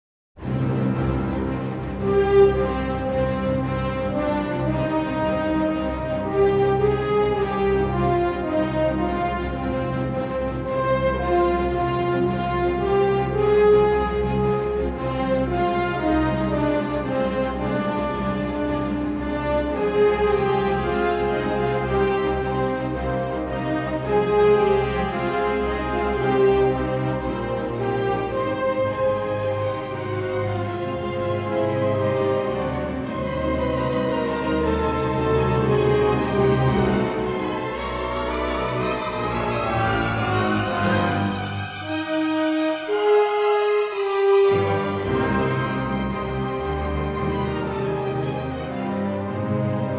★ 俄羅斯近代重要作曲家，曾教出普羅高菲夫、哈察都量等著名學生，交響曲與芭蕾舞曲都有俄羅斯風格旋律與多采多姿的音樂呈現。
為了聲音效果，樂團還坐得比一般狀況下更寬更開闊些。
Allegro pesante (13:28)audio_joy.JPG